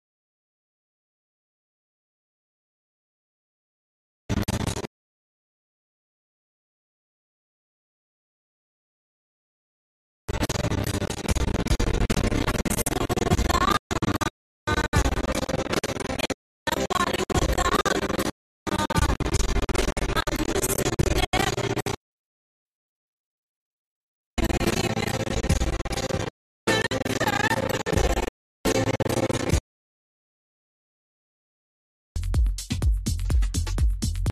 Replay of a live session